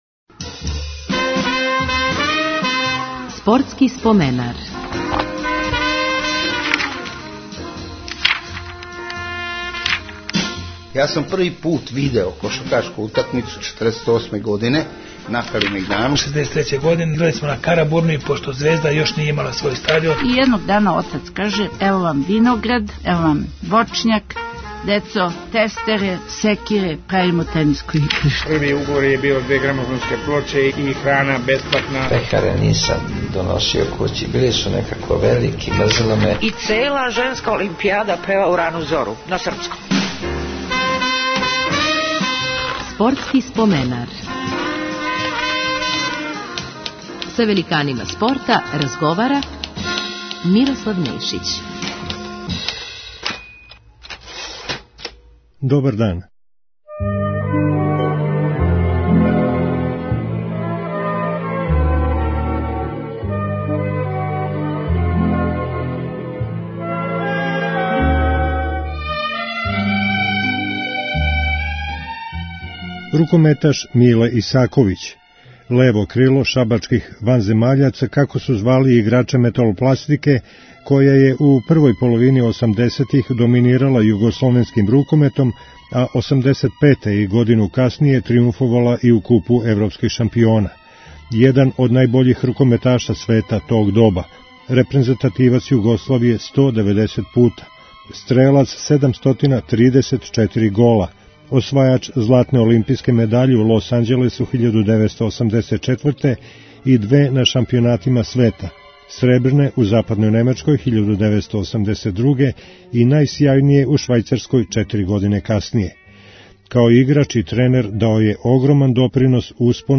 Гост 441. емисије је Миле Исаковић, један од најбољих светских рукометаша 80-их.